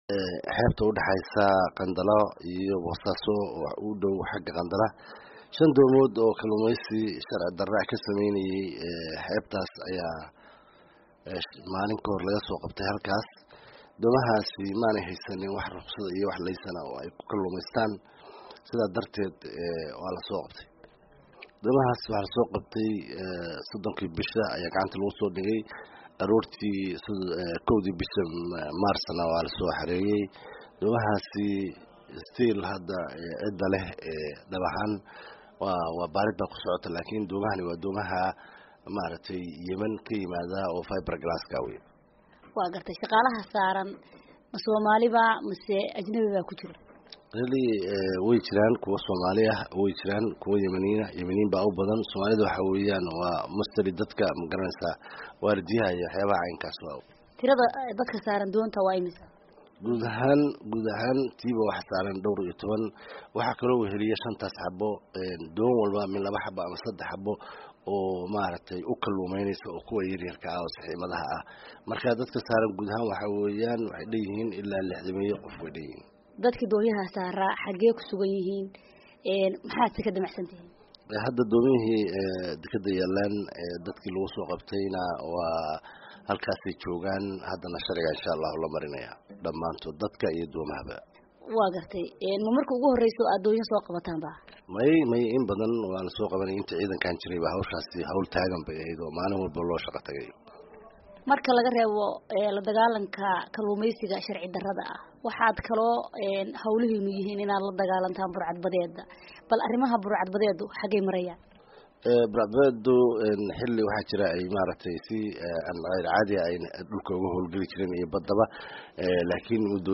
shirka Jaraa’id kadib taliyaha Ciidaamaad badda Puntland General C/risaaq Diiriye Faarax, waxayna ugu horayn weydiisay tirada doonyaha ay soo qabteen, dadka saaran iyo halka ay ka soo qabteen.